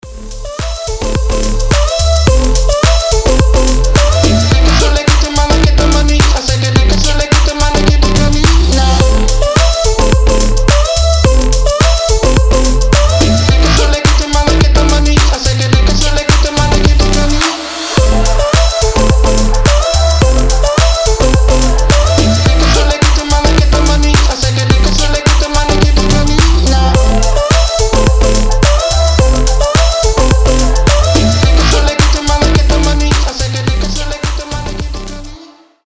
• Качество: 320, Stereo
Electronic
EDM
future house
Dance Pop
басы
Заводной future house/pop